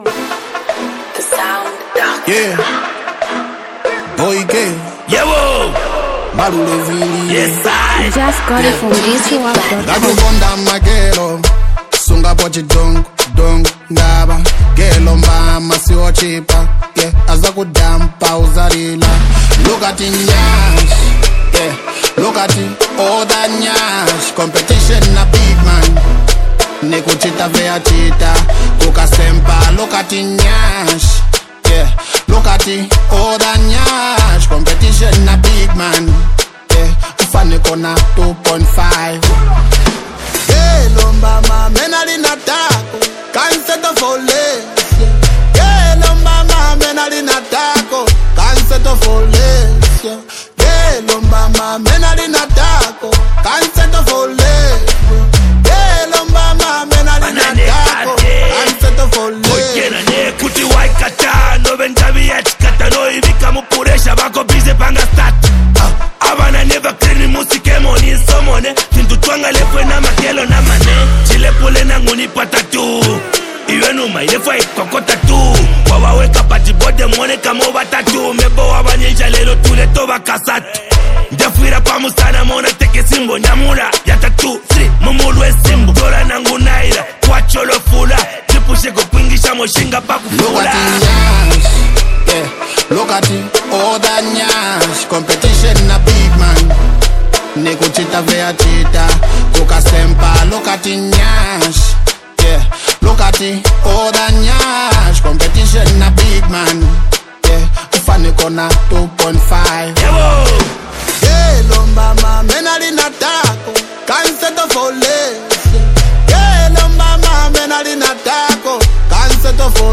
Copperbelt up rising talented rap sensation artist
ghetto vibing genre
blend of Afro-pop & beat